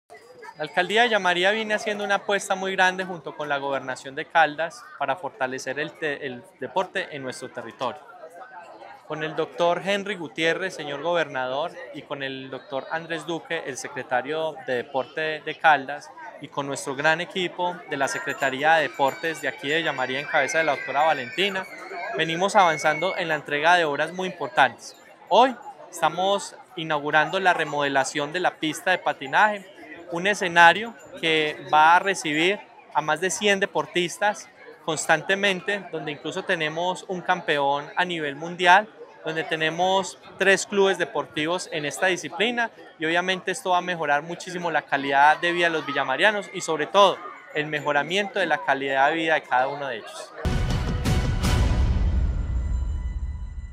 Jonier Alejandro Ramínez, alcalde de Villamaría.